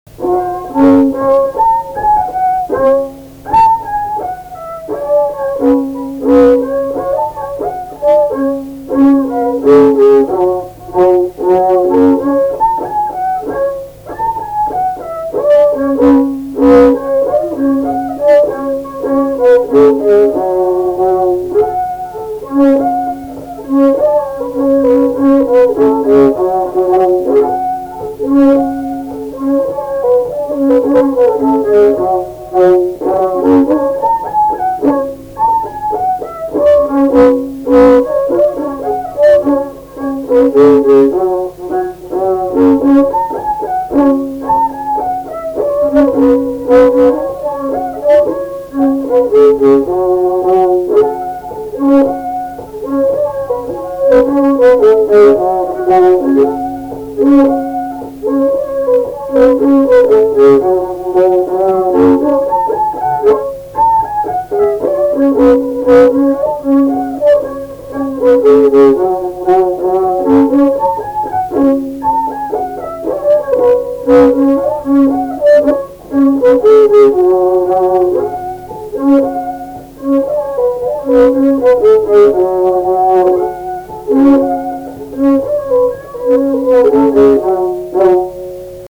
instrumentinis
smuikas
LMTA Mokslo centro muzikinio folkloro archyvas